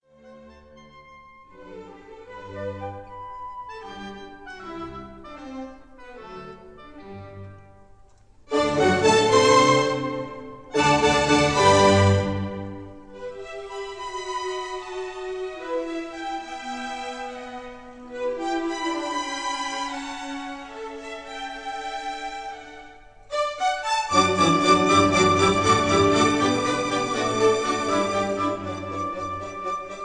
in D major